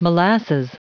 Prononciation du mot molasses en anglais (fichier audio)